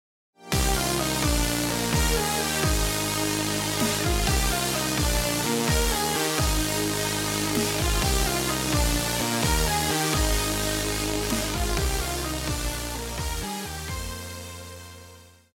套鼓(架子鼓)
乐团
演奏曲
电子音乐,流行音乐
独奏与伴奏
有主奏
有节拍器